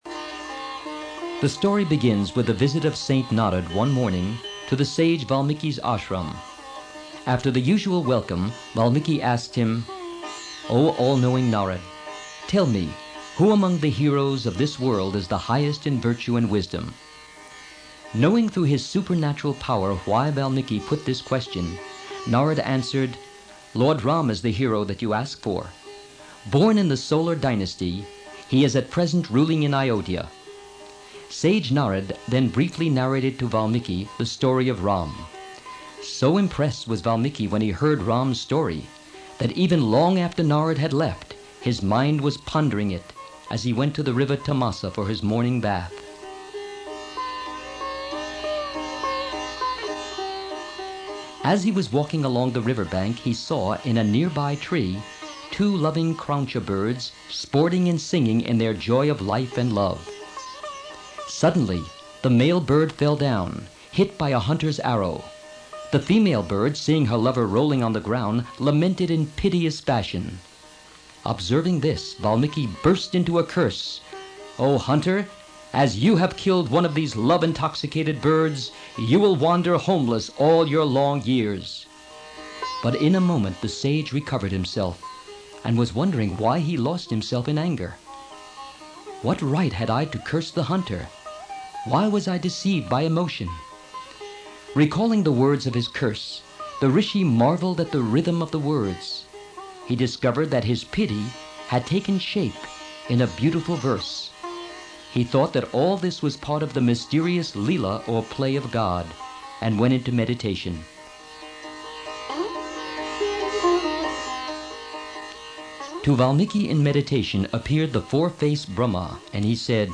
A Dramatic Narration of India's Glorious Epic
Erzählung (engl.) im MP3-Format, mit indischer Hintergrundmusik, Inhaltsverzeichnis auf der CD vorhanden.
Hinweis: während der Übertragung von den Original-Kassetten in's MP3-Format sind kleine Qualitätseinbussen entstanden.